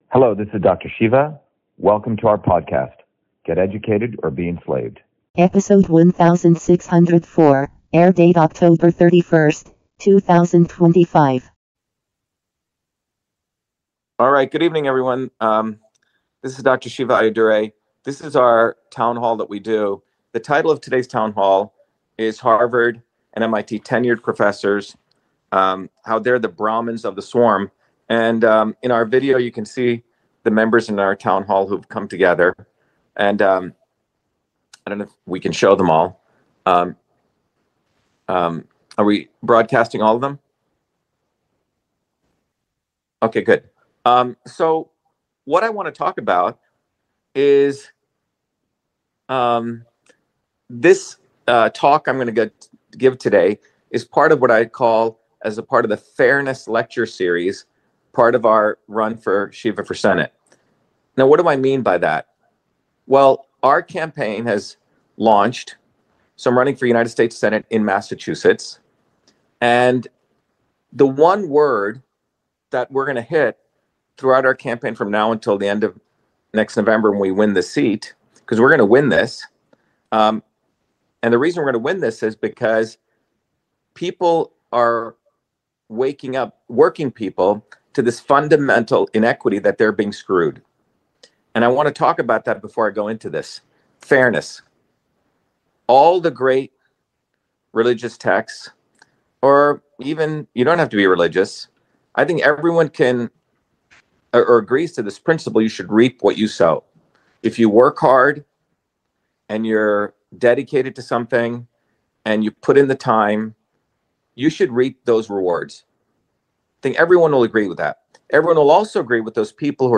In this interview, Dr.SHIVA Ayyadurai, MIT PhD, Inventor of Email, Scientist, Engineer and Candidate for President, Talks about Harvard and MIT Tenured Professors Brahmins of the SWARM